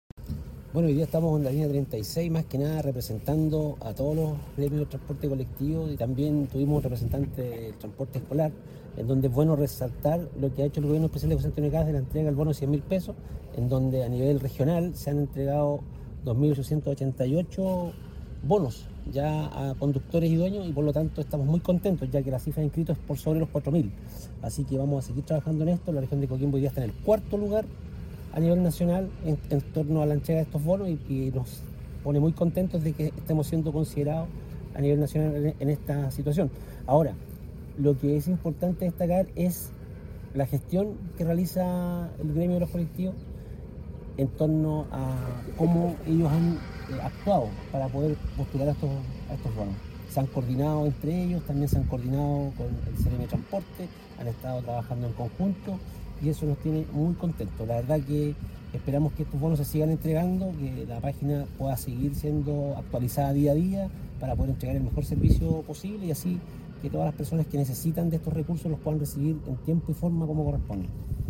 BONO-TRANSPORTISTAS-Delegado-Presidencial-Regional-Victor-Pino.mp3